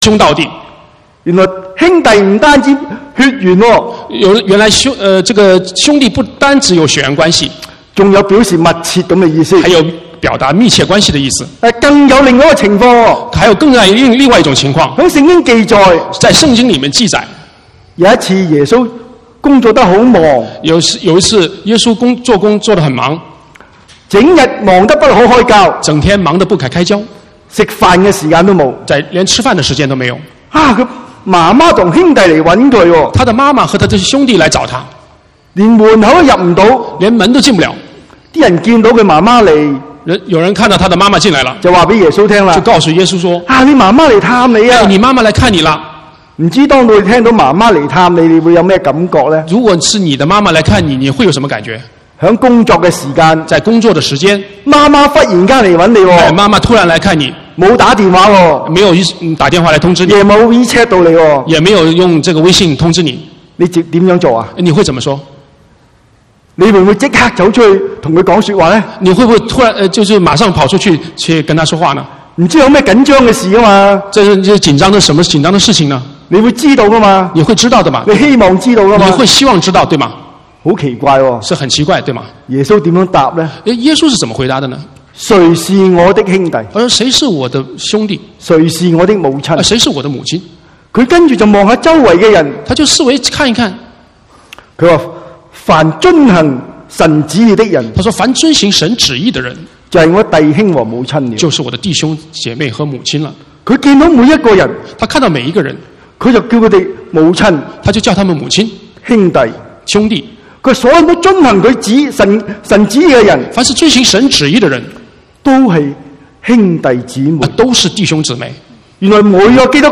27/10/2019 國語堂講道